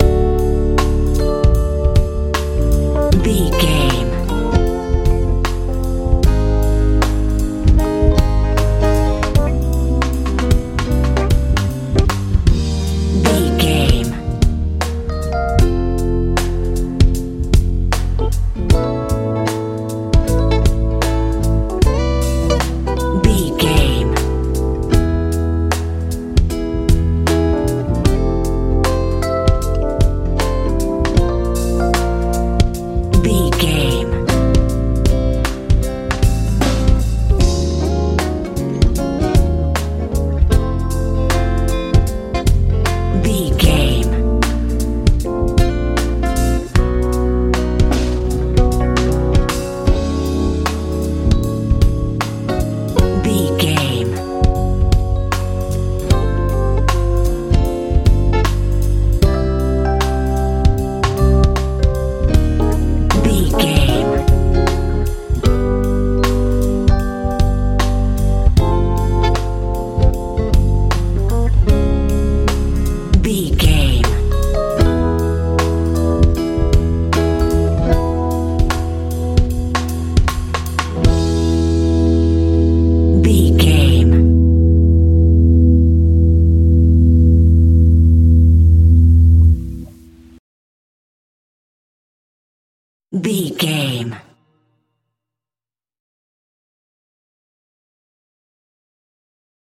rnb soul feel
Ionian/Major
D
groovy
smooth
organ
electric guitar
bass guitar
drums
70s
80s